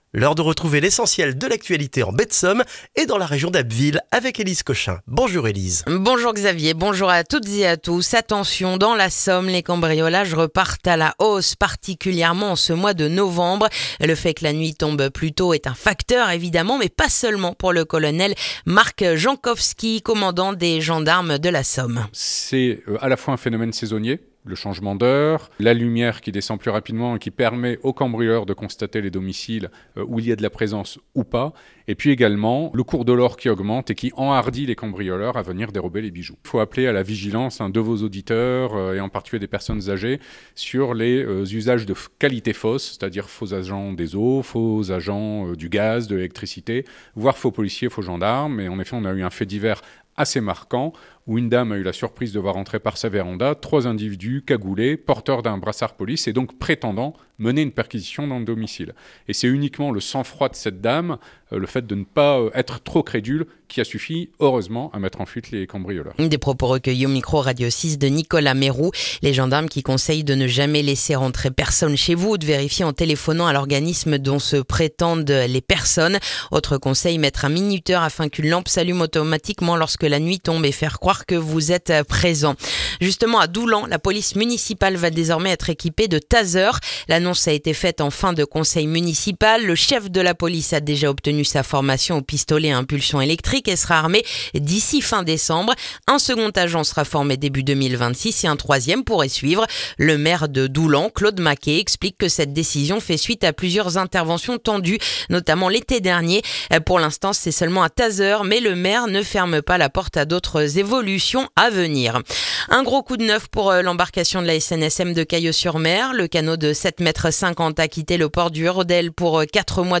Le journal du lundi 24 novembre en Baie de Somme et dans la région d'Abbeville